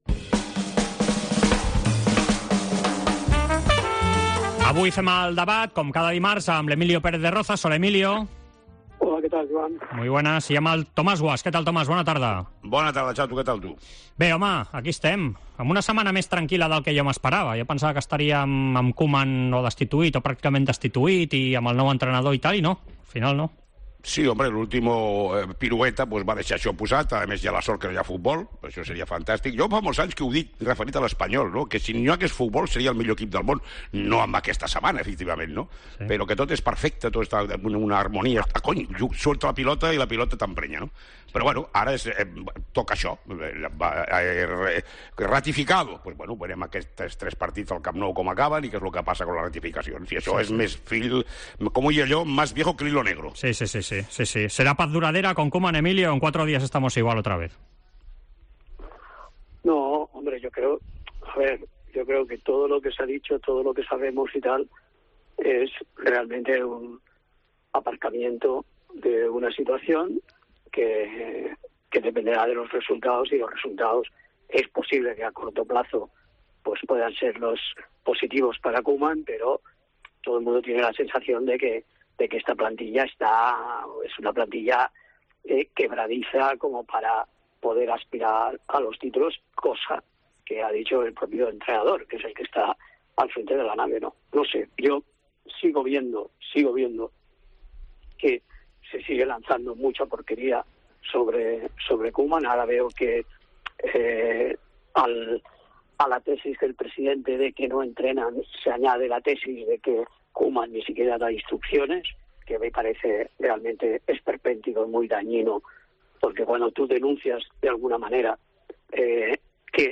Debat